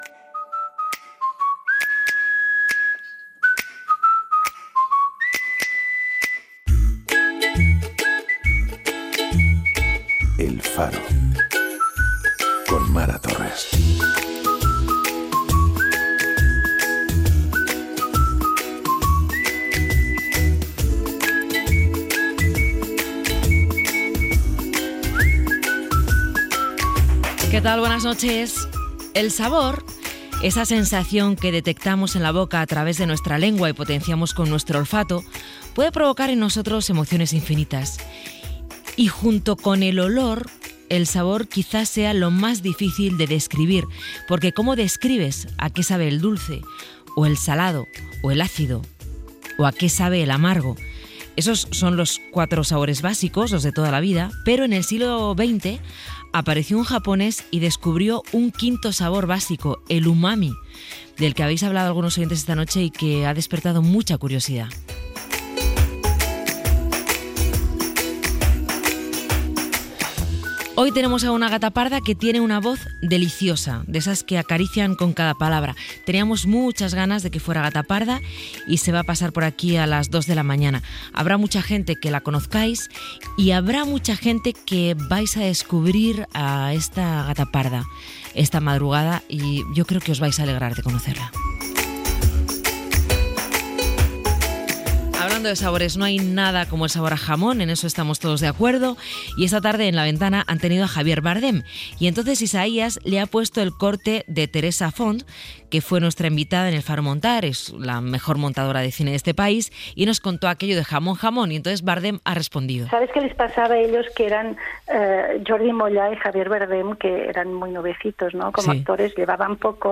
Careta del programa, espai dedicat al sentit del gust, fragment de "La ventana" sobre la pel·lícula "Jamón, jamón" amb l'actor Javier Bardem, identificar un so, tema musical
Entreteniment